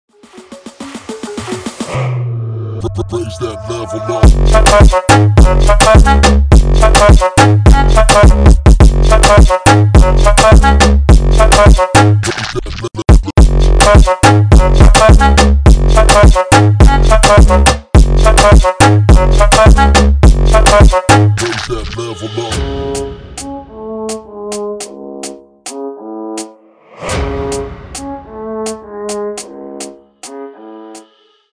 • Качество: 128, Stereo
ритмичные
remix
Brazilian bass
Moombahton
латина
танцевальные